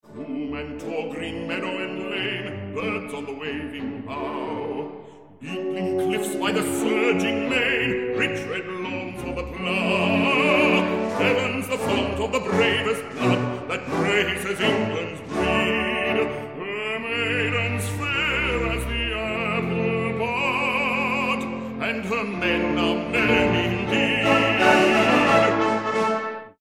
Bass Baritone